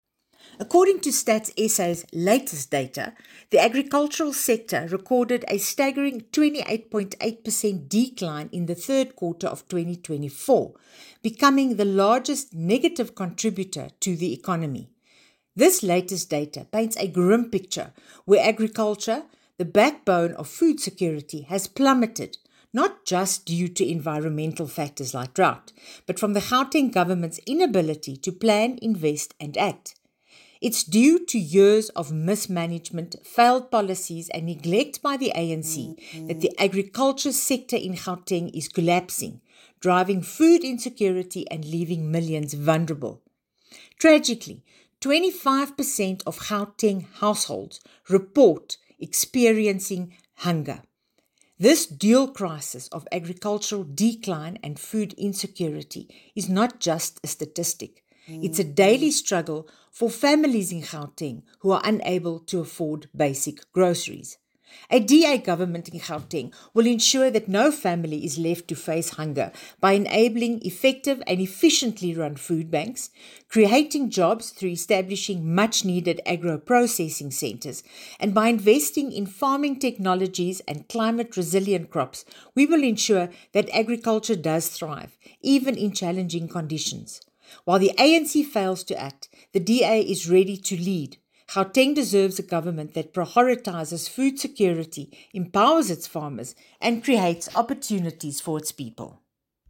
Issued by Bronwynn Engelbrecht MPL – DA Gauteng Shadow MEC for Agriculture and Rural Development
Note to Editors: Attached is a soundbite in